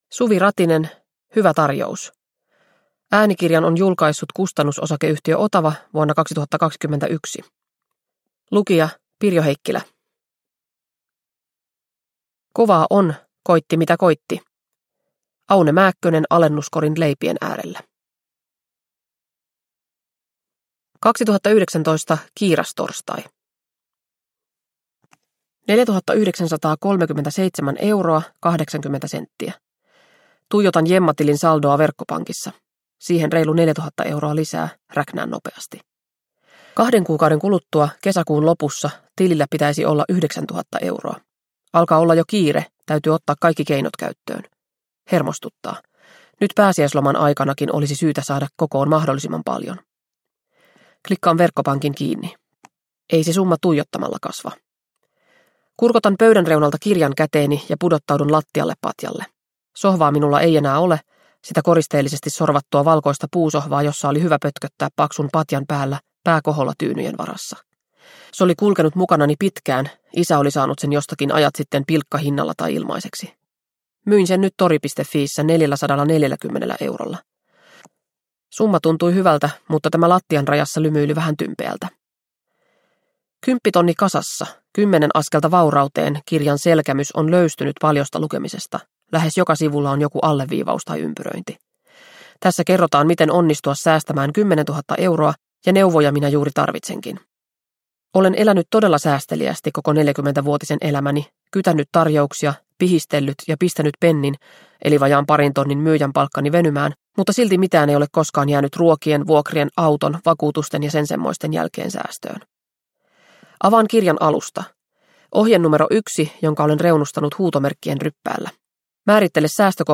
Hyvä tarjous – Ljudbok